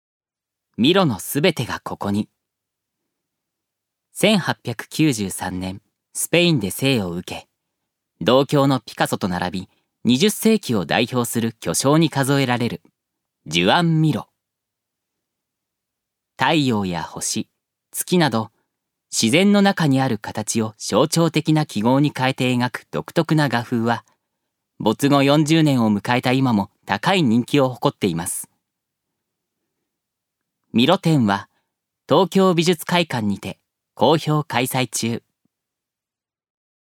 預かり：男性
ナレーション１